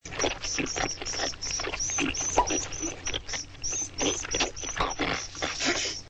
the sound of plunging a toilet recorded on an old 8-track and played backwards.
creature-cutting.mp3